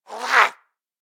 DayZ-Epoch/SQF/dayz_sfx/zombie/spotted_7.ogg at ec1bebb2c97efebee99ca92a234e8535df055cfe
spotted_7.ogg